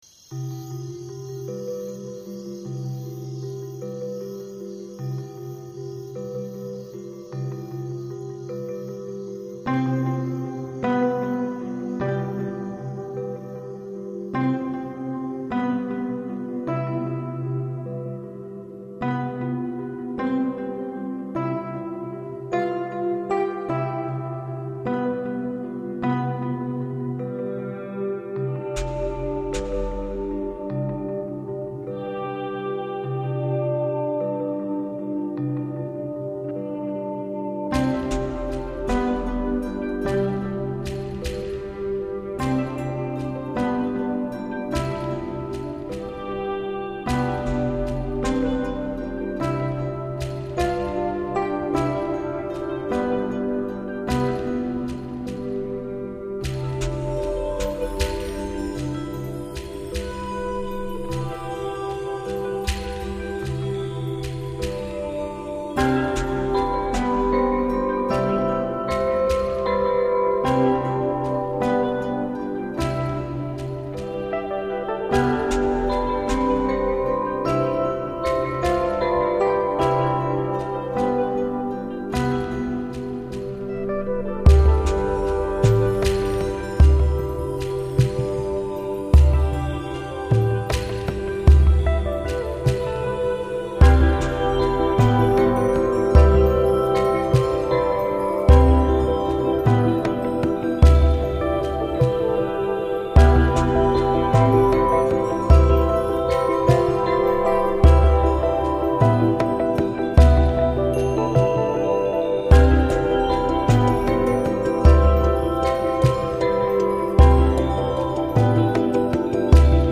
シンセの音はこんなにもまろやかで。
そこでは音がコロコロと走り。
在那里乐音如珠走玉盘、飞沫四溅一般。